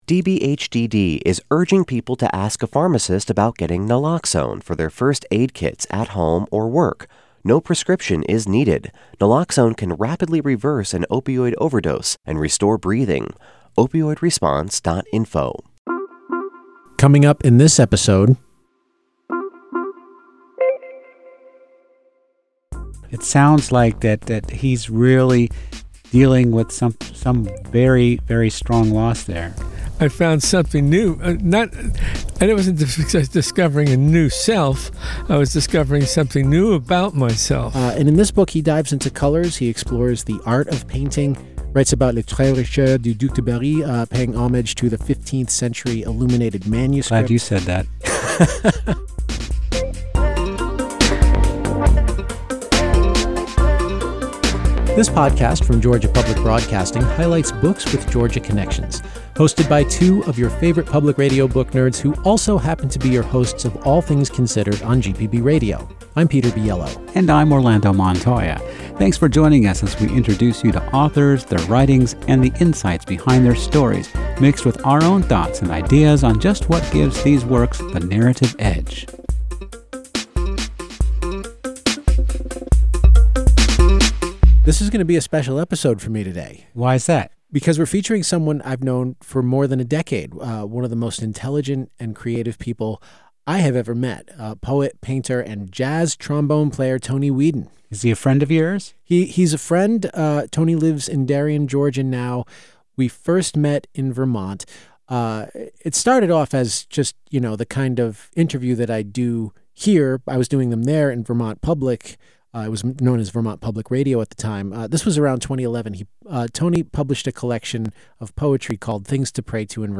… continue reading 46 episoade # Society # Books # Arts # Georgia Public Broadcasting # Lifestyle # Hobbies # Read # Review # NPR # GPB # Interviews # Authors